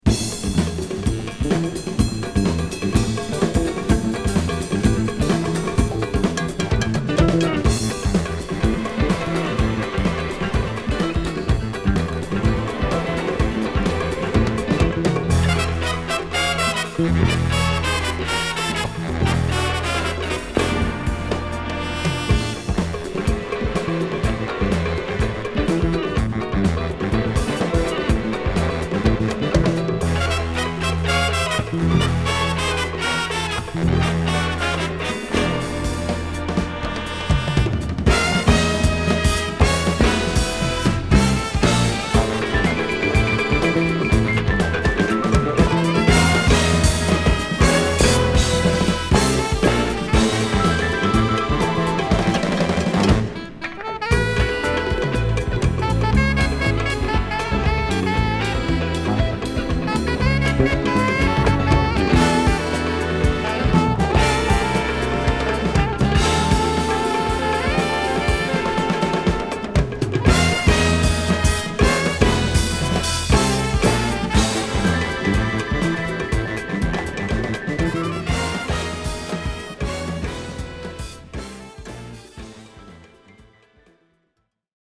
’75年LIVE録音。
最高なJAZZ FUNK～SOULカヴァー！